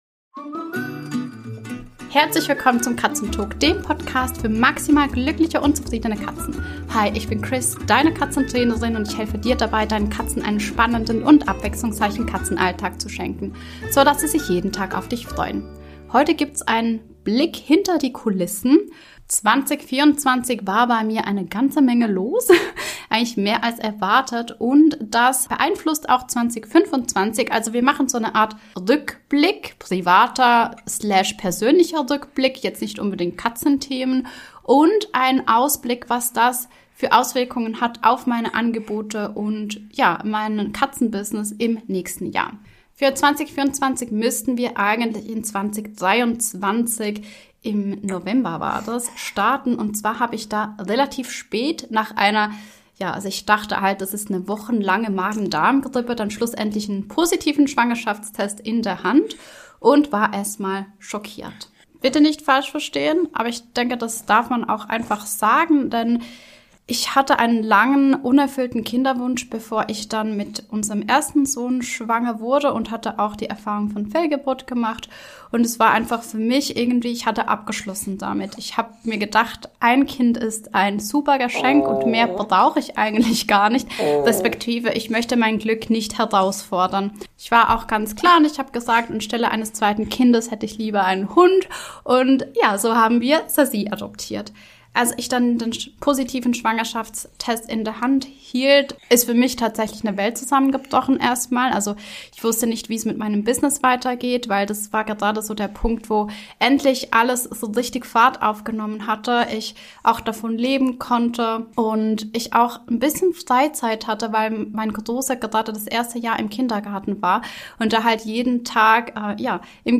Außerdem erfährst du, welche spannenden Neuerungen im kommenden Jahr auf dich warten, ob im Podcast, meinem Club oder meinen Kursen. Hinweis: Mein Baby war bei dieser Aufnahme mit an Bord und der Kleine hat wieder gezeigt, dass er irgendwann auch mal einen Podcast haben möchte Heute erfährst du…